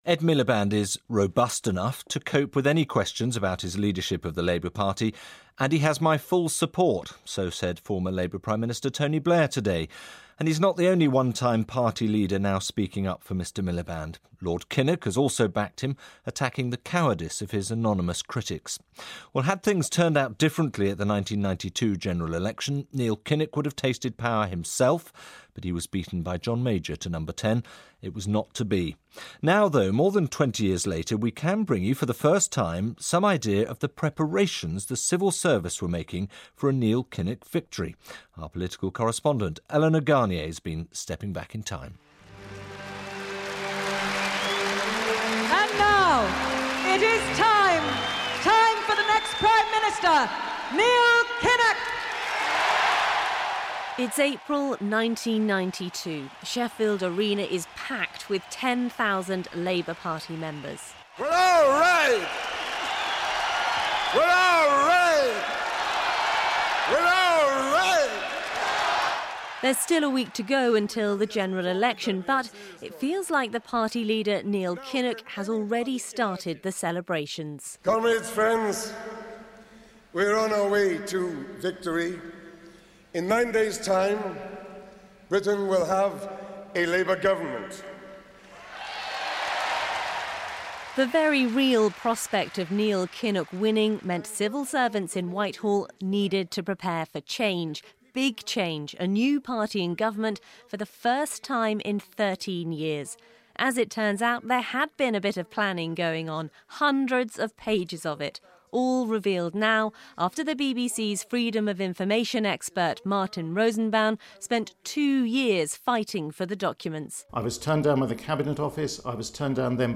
report for Radio 4's World Tonight on the civil service papers preparing for a Neil Kinnock victory in 1992.